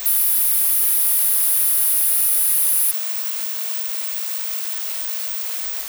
Запись 15-ти канального сигнала "Акула" ВМФ РФ.